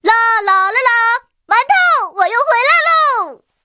M4A3E2小飞象战斗返回语音.OGG